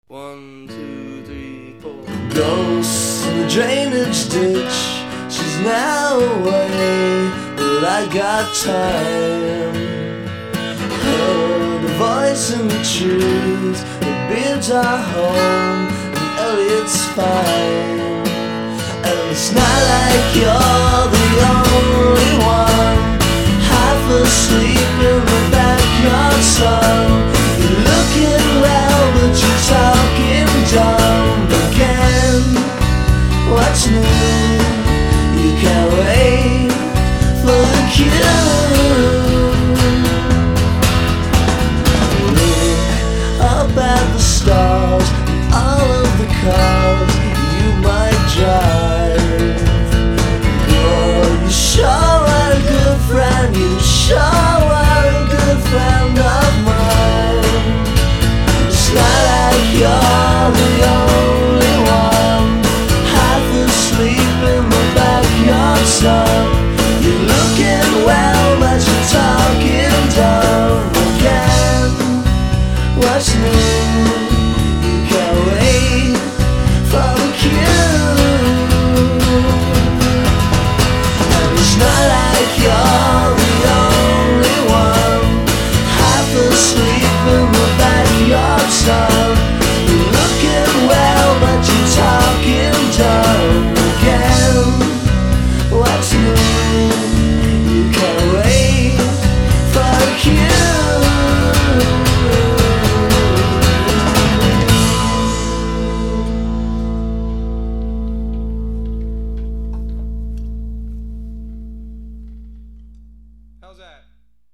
The songs stand in between folk and...I dunno, pop?